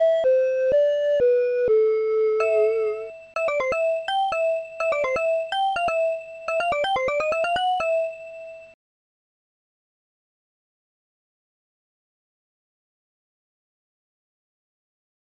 Protracker Module
PANFLUTE.SAM